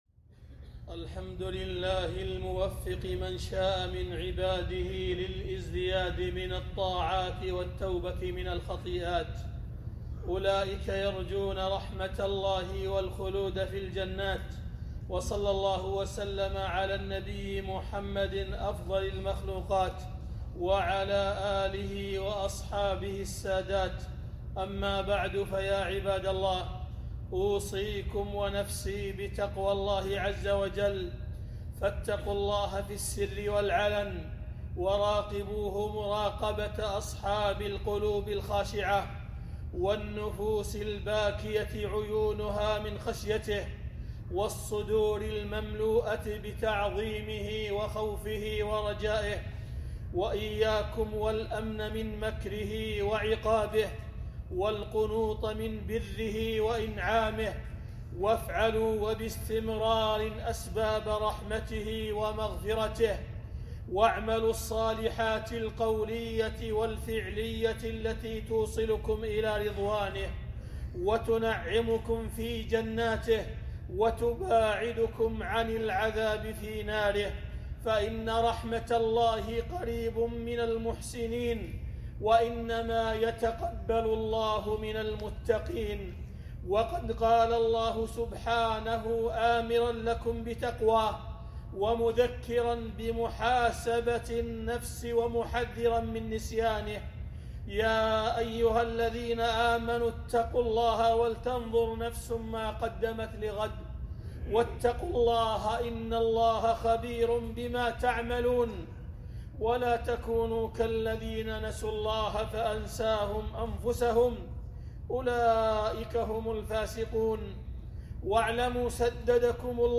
خطبة - فضل صيام شعبان وفوائد صيام التطوع وأحكام قضاء ما فات من رمضان